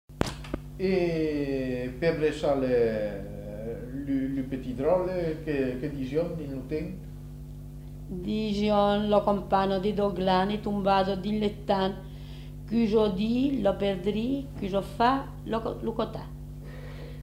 Aire culturelle : Périgord
Genre : forme brève
Type de voix : voix de femme
Production du son : récité
Classification : formulette enfantine